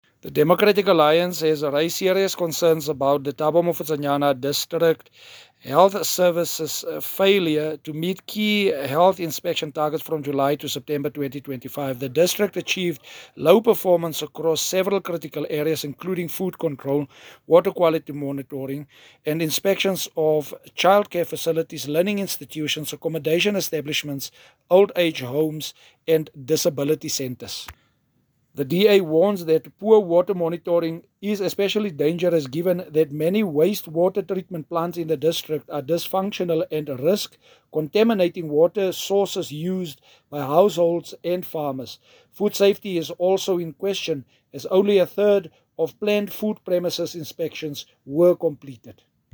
English and Afrikaans soundbites by Cllr Marius Marais and